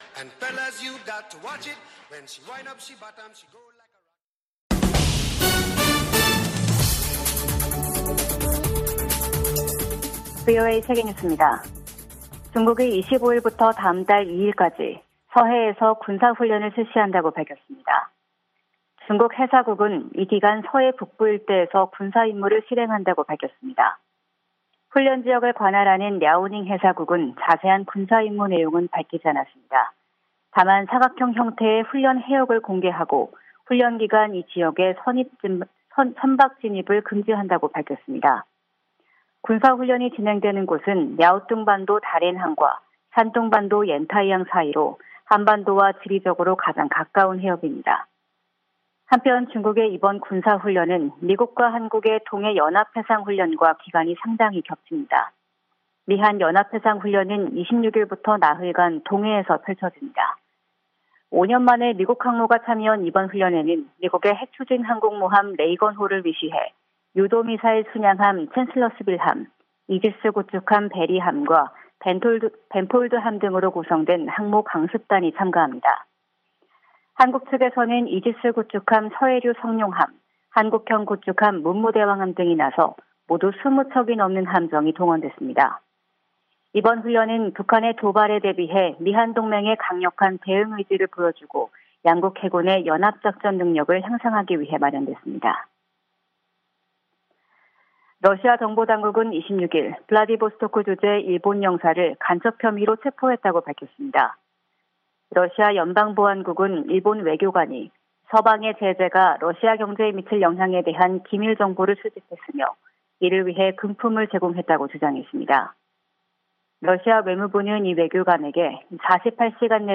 VOA 한국어 아침 뉴스 프로그램 '워싱턴 뉴스 광장' 2022년 9월 27일 방송입니다. 한국 정부가 북한의 탄도미사일 도발을 규탄했습니다. 미 국무부도 북한 탄도미사일 발사를 비판하며 유엔 안보리 결의 위반임을 강조했습니다. 북한 신의주와 중국 단둥을 오가는 화물열차가 150일 만에 운행을 재개했습니다.